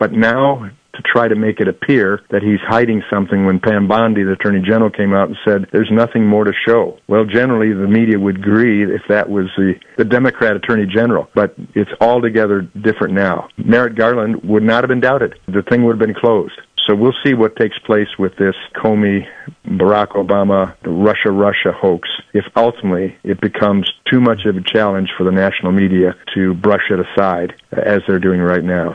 US Congressman Tim Walberg regularly joins A.M. Jackson Friday mornings.